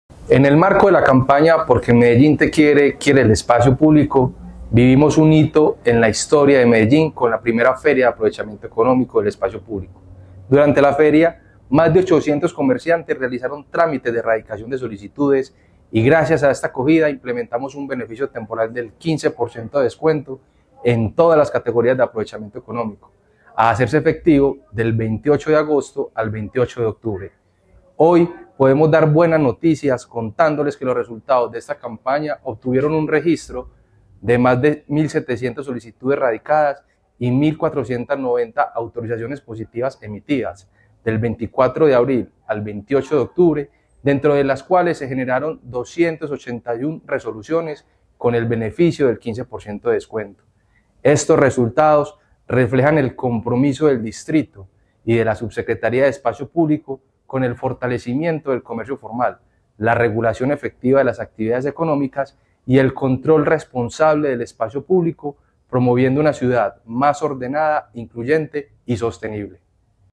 Declaraciones del subsecretario de Espacio Público, David Ramírez Desde el inicio de 2024, Medellín ha dado pasos firmes en la regulación y optimización del Aprovechamiento Económico del Espacio Público (AEEP) .
Declaraciones-del-subsecretario-de-Espacio-Publico-David-Ramirez-1.mp3